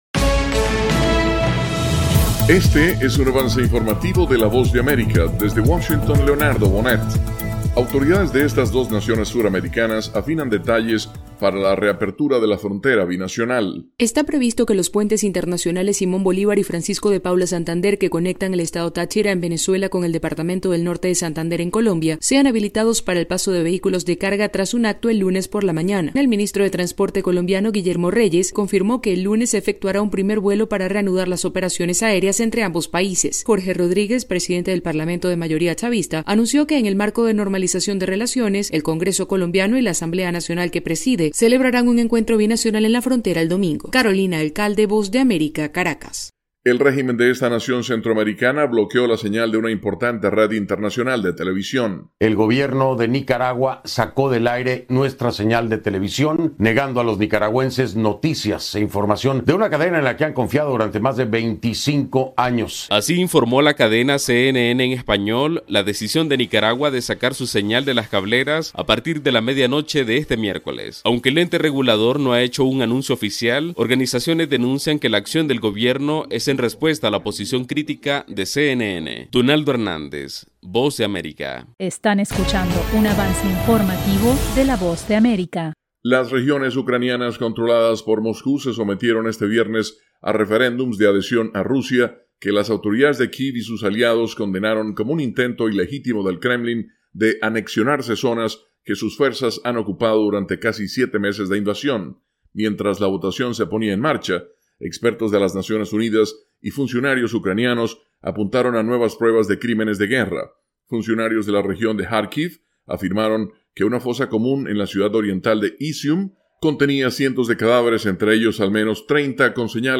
Avance Informativo 3:00 PM